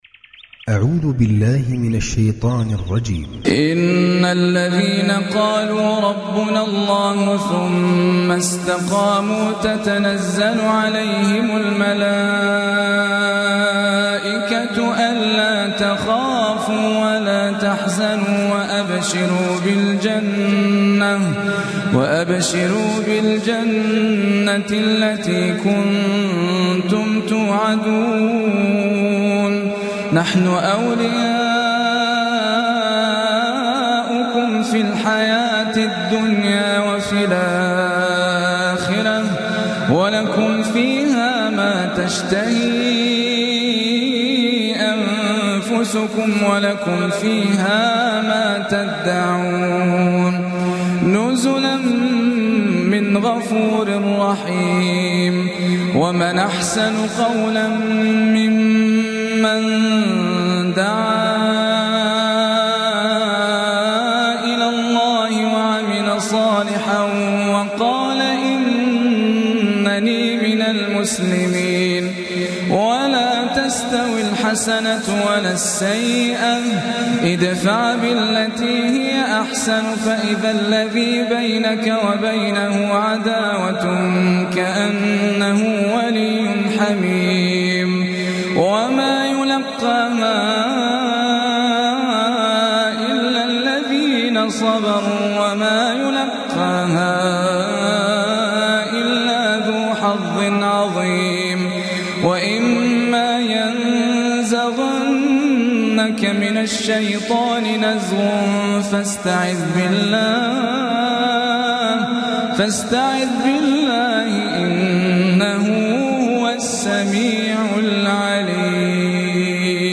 Recitime